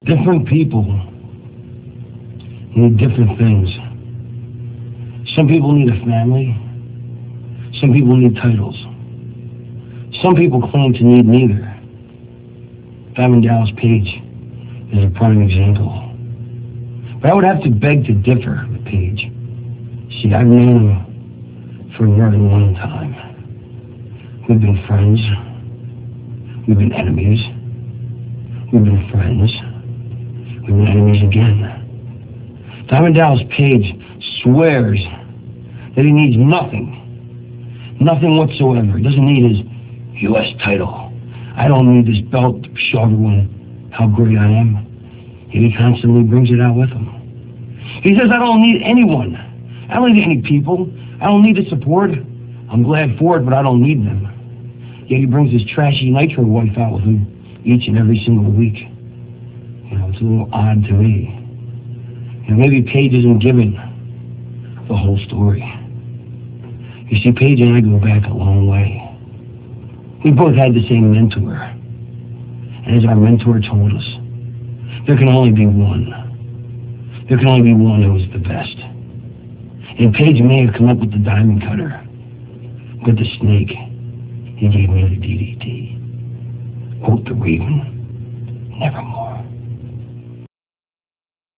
- This speech comes from WCW Saturday Night - [1.31.98]. It is from a promo video that talks about how Raven doesn't need the fans' support like DDP does to win matches.